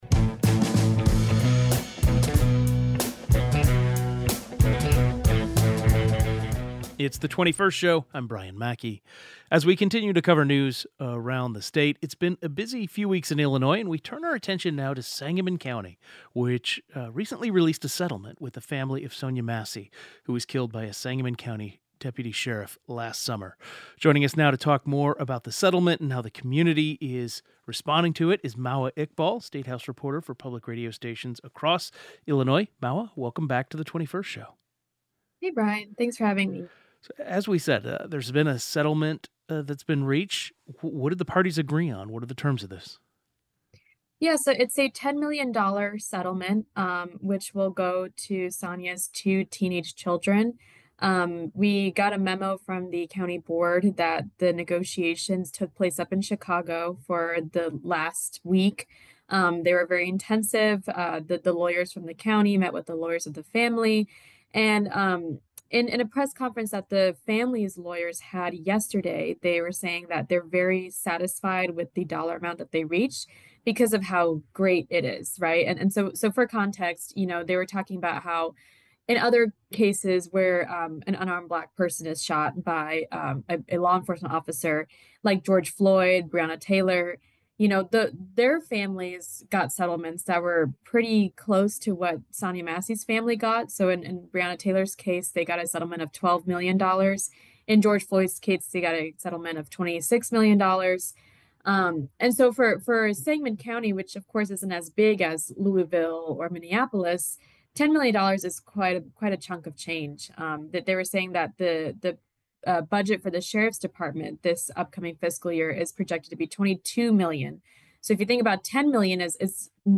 Our Friday Politics Reporter Roundup focused on major stories out of Springfield and Washington, DC.